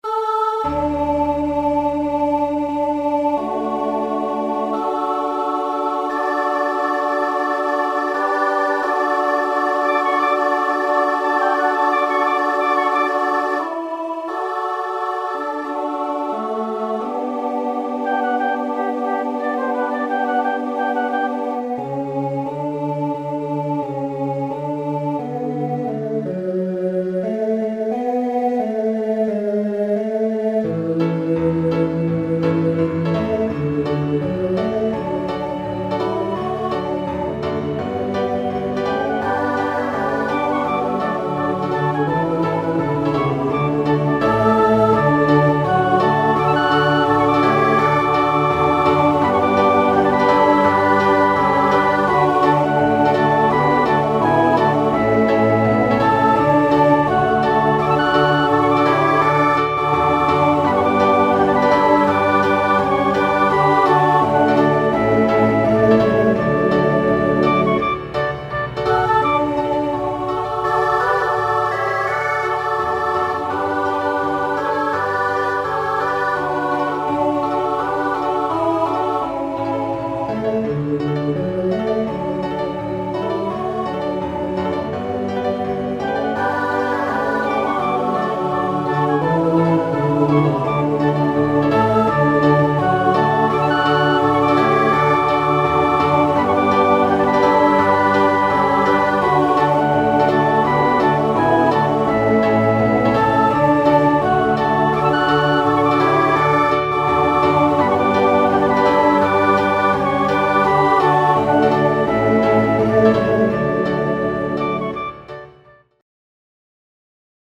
Scored for: SATB choir with piano and optional flute